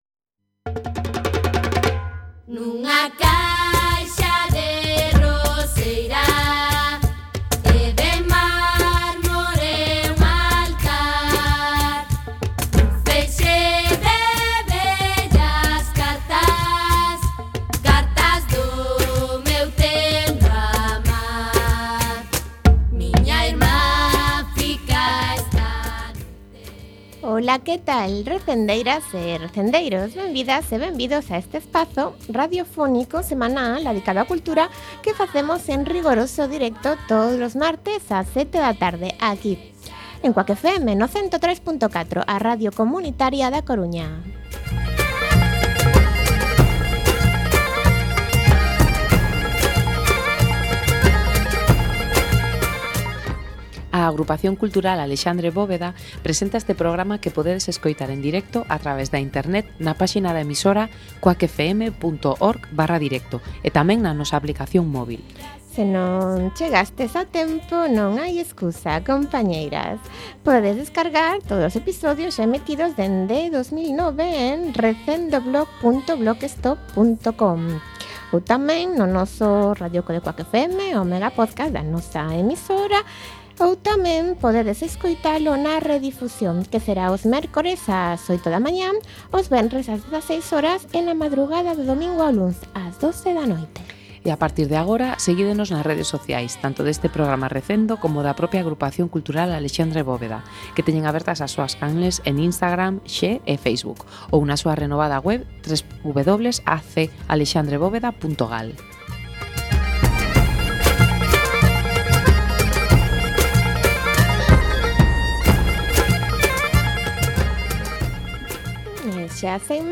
16x11 Entrevista